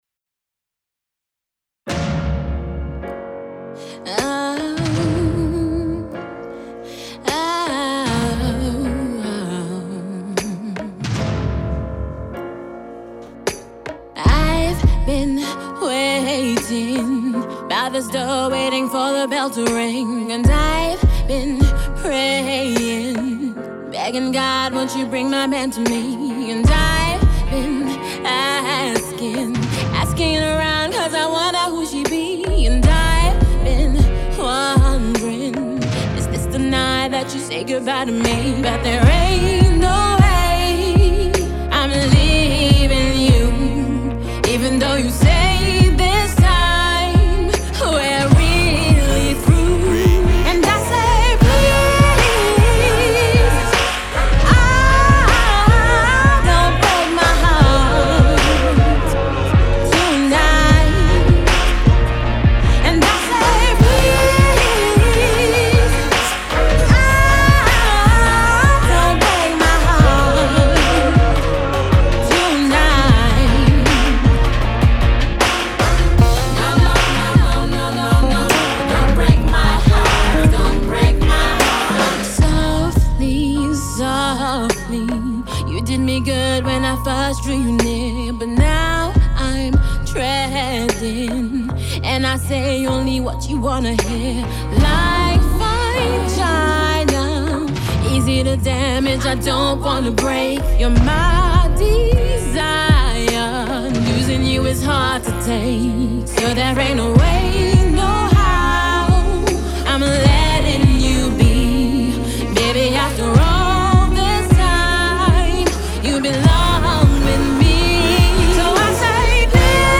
an alternative soul singer
a solemn love song